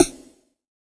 SNARE 05  -L.wav